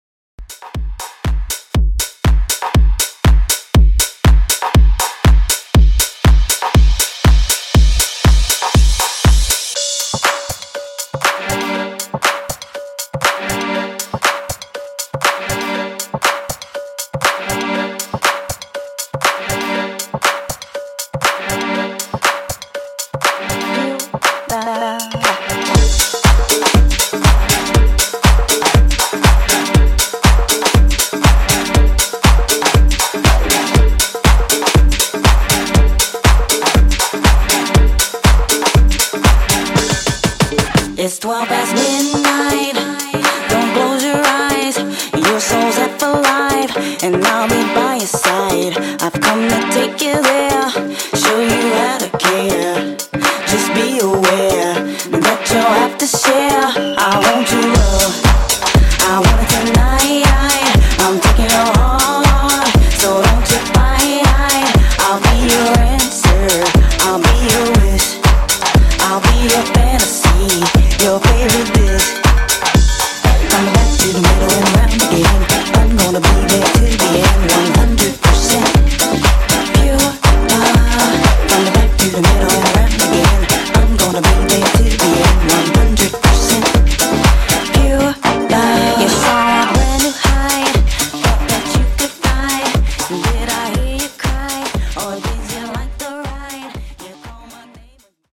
90s Classic House)Date Added